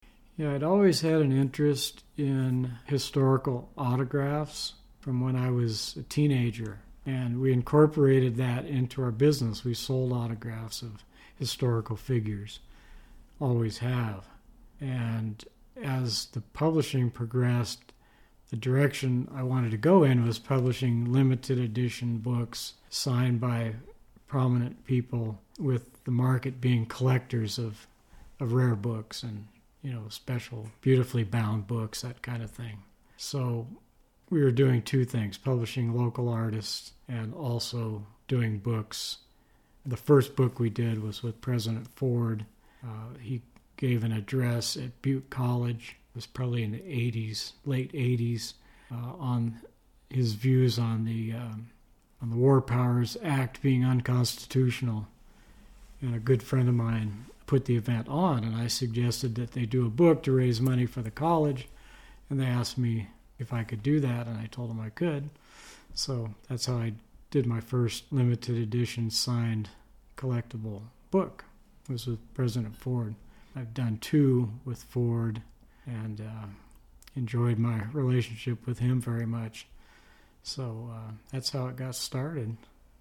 Interviewed in 2015